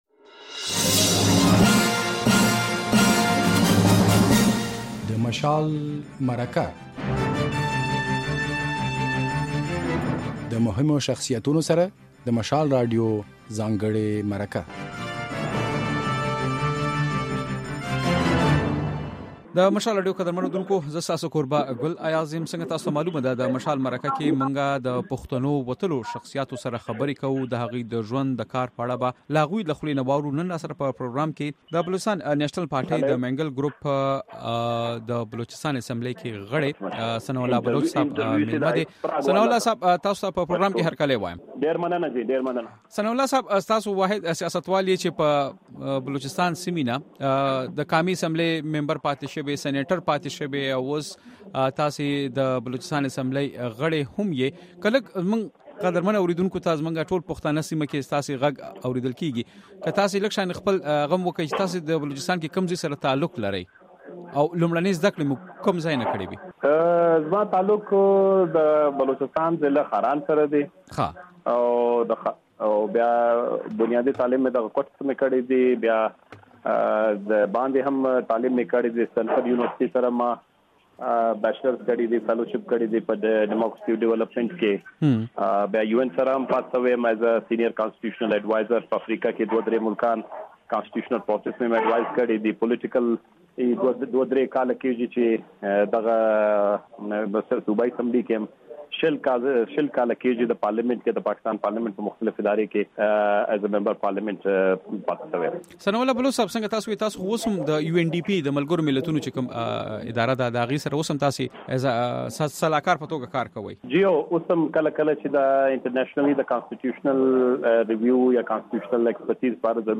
د مشال مرکه کې د بلوچستان نيشنل پارټۍ د مينګل ډلې صوبايي اسمبلۍ غړی ثناالله بلوڅ ميلمه دی.